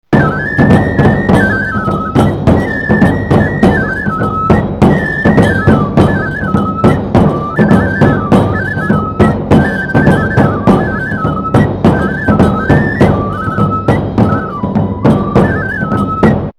nebutabayashi.mp3